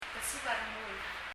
kesib a rengul [kɛsib ə rɛŋəl] 英） angry 日） 怒っている 辞書をみると、 kesib sweaty, perspiring 汗をかく・・・ rengul reng : heart, spirit. feeling, will, desire 感情・欲望・心 <・・・> a rengul という成句が Josephs の辞書では １ページ以上にわたって載っています。